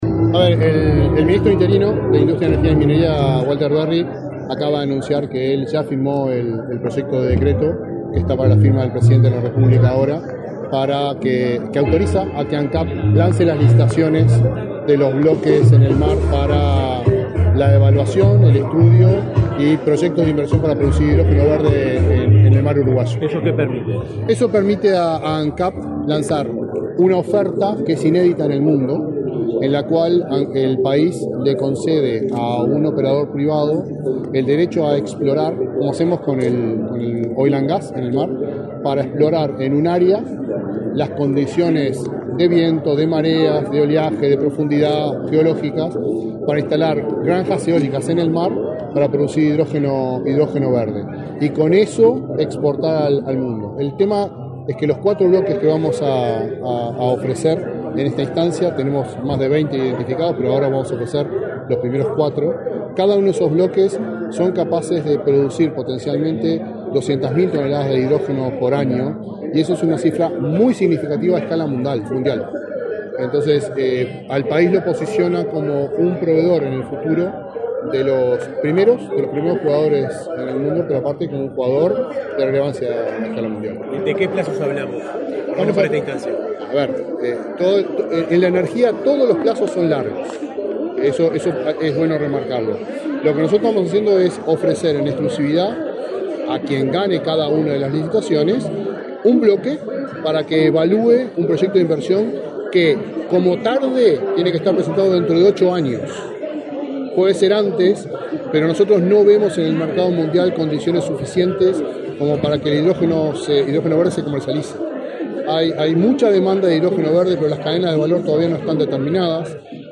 Declaraciones del presidente de Ancap, Alejandro Stipanicic
Este viernes 6, el presidente de Ancap, Alejandro Stipanicic, dialogó con la prensa, luego de participar en la inauguración del stand del ente